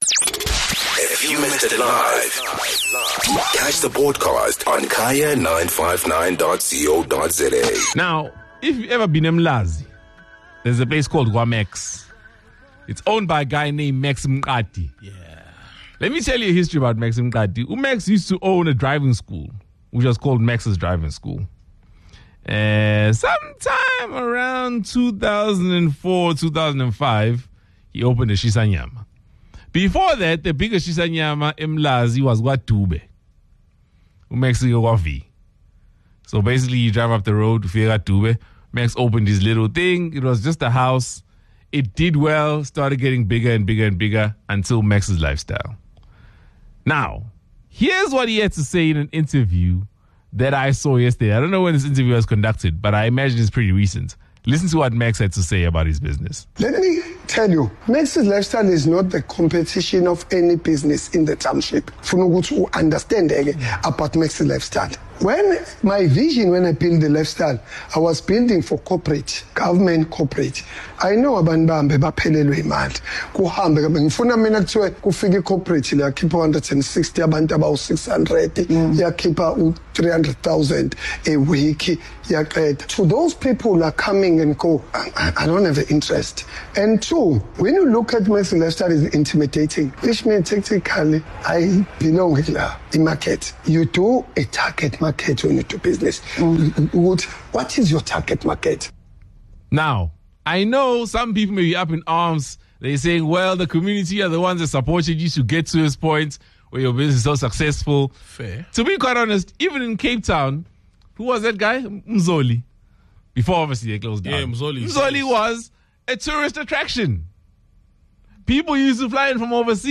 The team debated this approach to business.